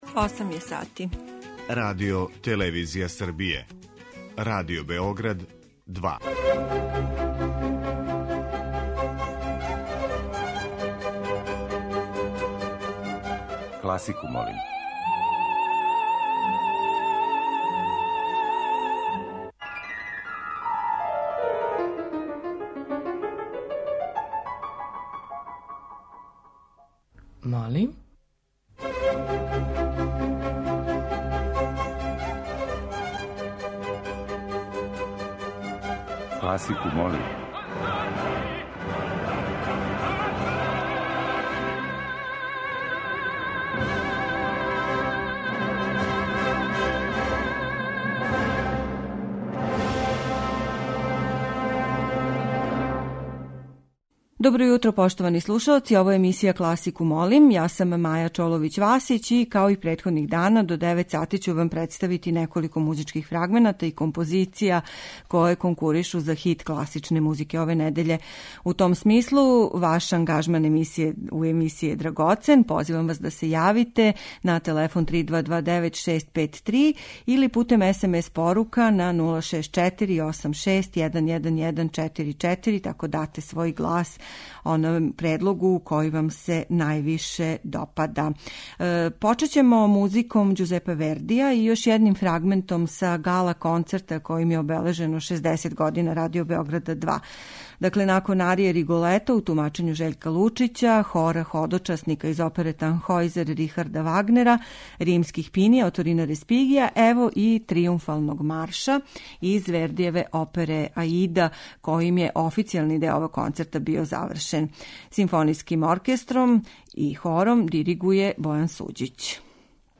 Гала концерт Хора и СО РТС-a којим је обележено 60 година Радио Беoграда 2
Нит која повезује овонедељни циклус је Гала концерт Хора и СО РТС-a којим је обележено 60 година Радио Беoграда 2 и на коме је као специјални гост наступио баритон Жељко Лучић. Један од предлога за хит недеље у свакој емисији биће композиција изведена на овој вечери 21. априла у Коларчевој задужбини.